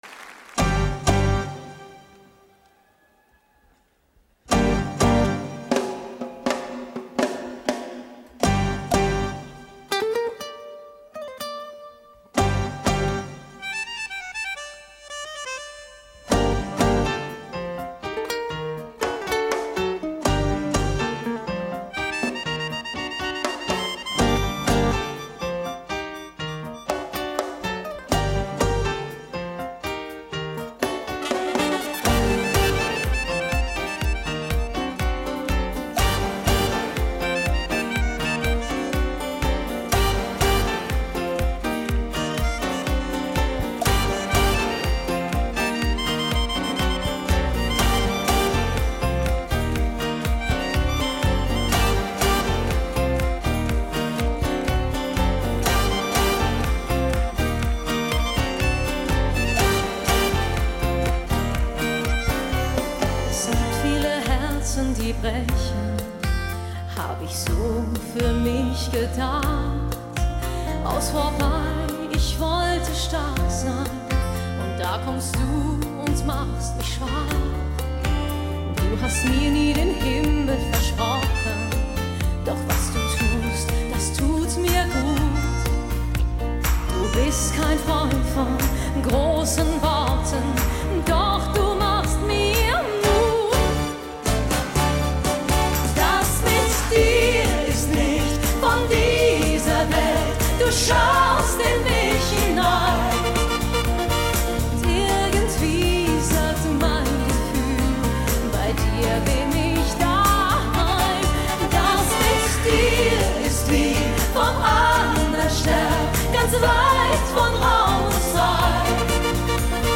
Genre: Pop, Schlager